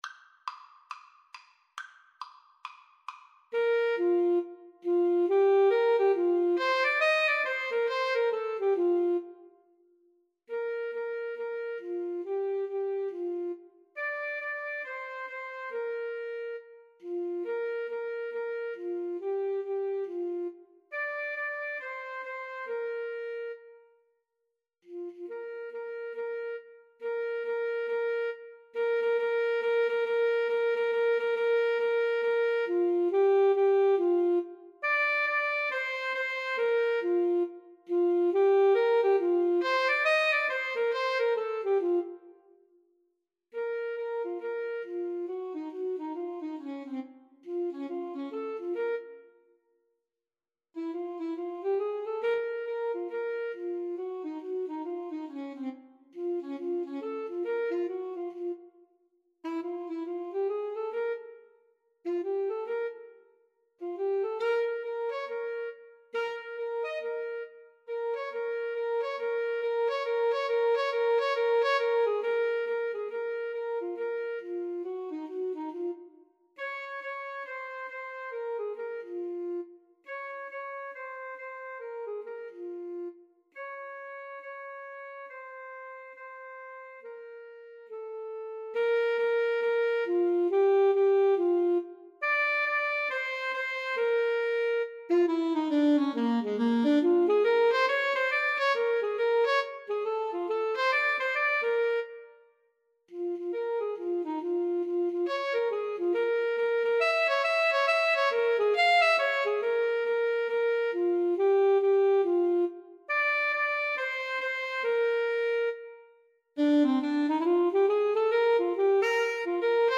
A jazzy take on this classic children’s tune!
Molto Allegro, Swung =c.69 (View more music marked Allegro)
jazz (View more jazz Alto Saxophone Duet Music)